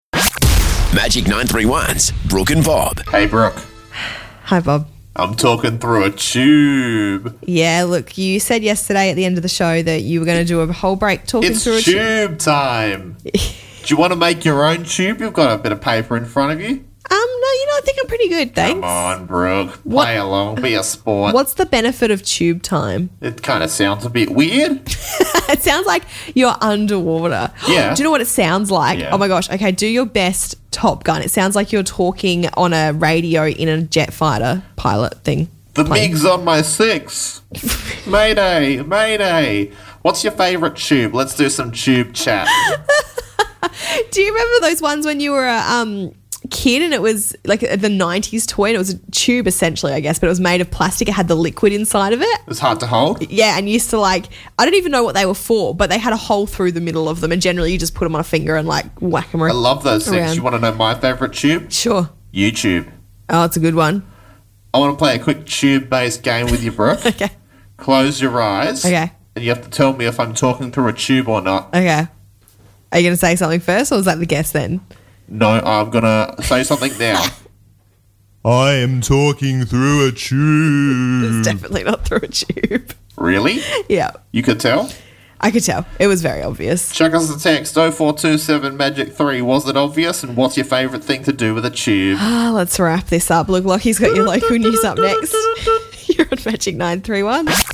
Radio sounds better through a tube, it's just a fact!